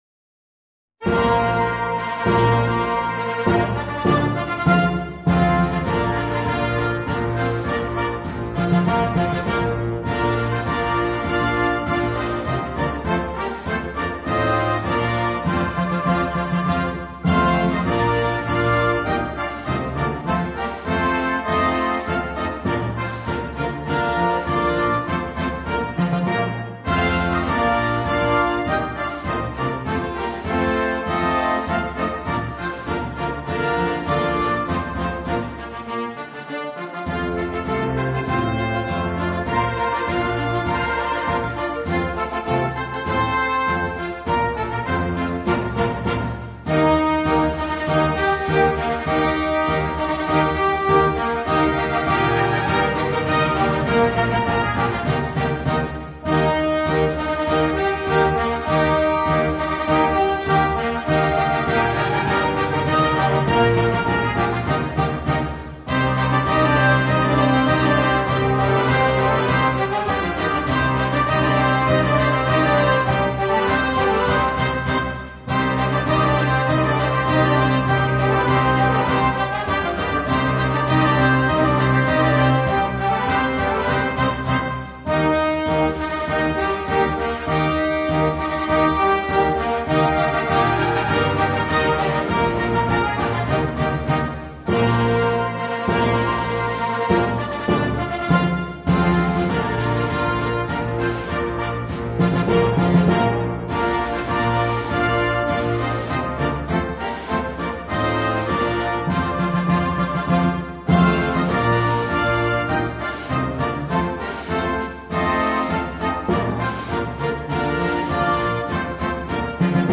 marcia militare
tradizione della musica da banda austro‑ungarica